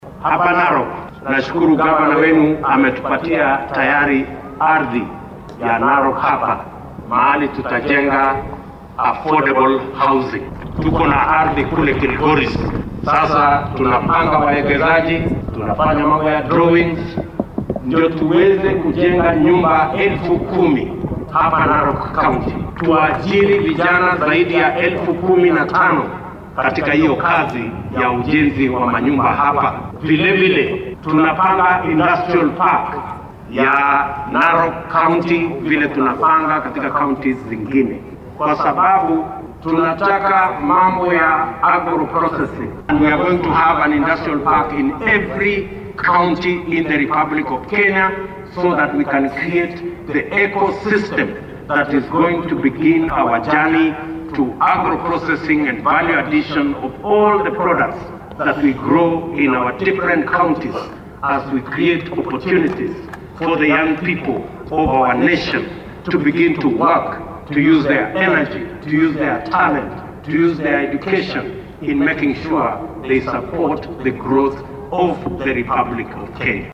Akizungumza alipozuru kaunti hii kwa hafla ya maombi ya shukrani iliyoandaliwa na gavana Patrick Ole Ntutu, Ruto alidokeza kwamba zaidi ya vijana elfu 15 wataajiriwa ili kuendesha ujenzi wa nyumba hizo.
Rais-William-Ruto-on-nyumba-za-bei-nafuu-Narok.mp3